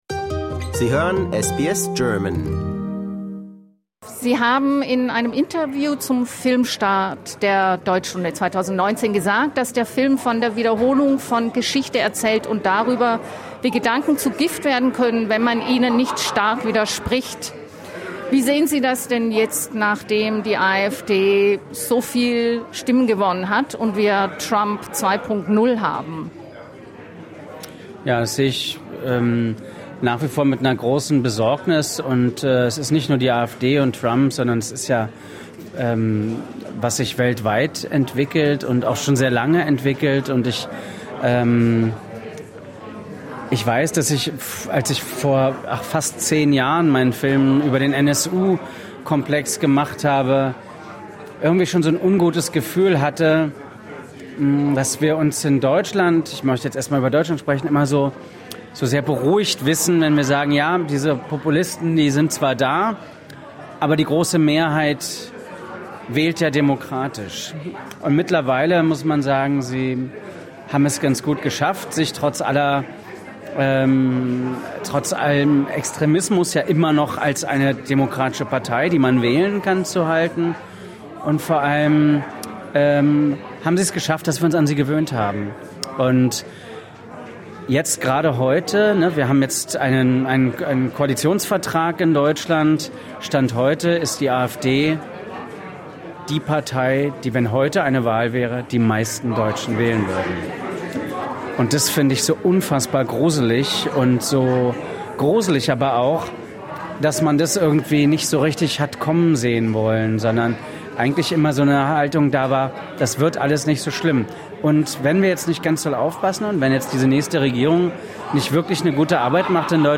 We met Schwochow on the sidelines of a screening of his film “The German Lesson”, organized by the Goethe Institute Australia. We talk to him about the significance of the film “The German Lesson” in times of democratic challenges and about what is special about filming in Australia. For more stories, interviews, and news from SBS German, discover our podcast collection here.